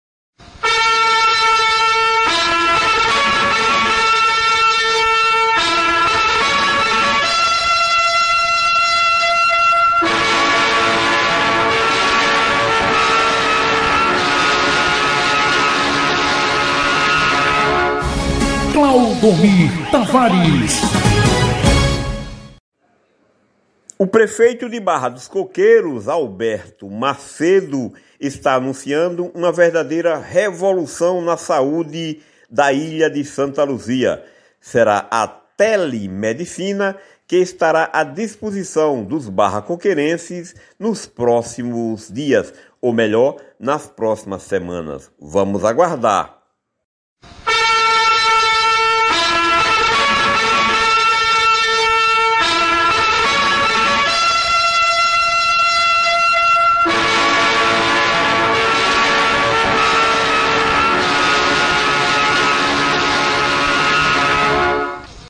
O prefeito Alberto Macedo (MDB) anunciou em entrevista concedida domingo, 19, à rádio Tribuna da Praua, a implantação nas próximas semanas da Telemedicina em Barra dos Coqueiros, o que se reverterá em uma revolução na prestação dos servicos de saúde pública para a população da Ilha de Sants Luzia.